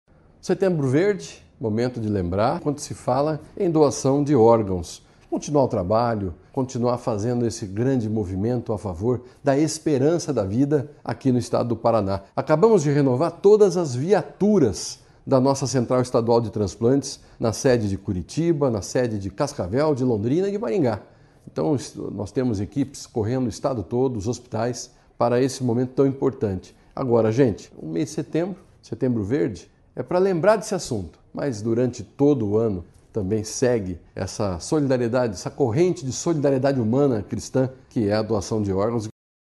Sonora do secretário da Saúde, Beto Preto, sobre a campanha Setembro Verde